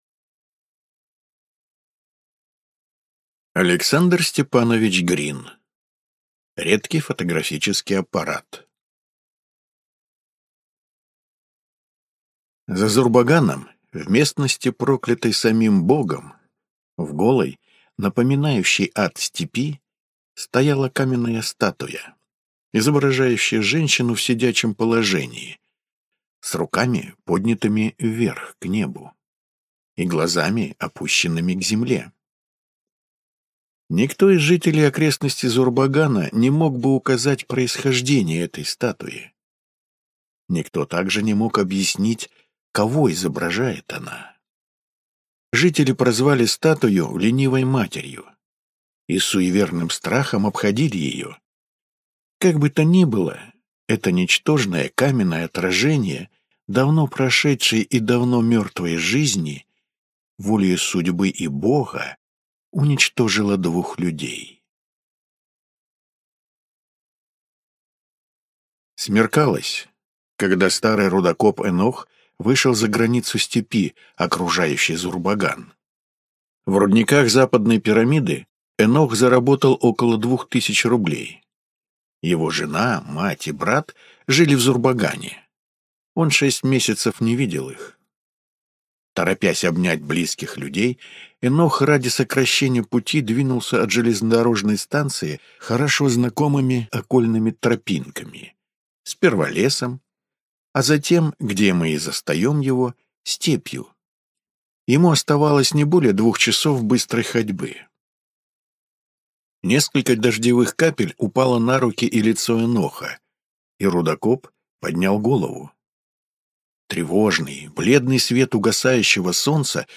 Аудиокнига Редкий фотографический аппарат | Библиотека аудиокниг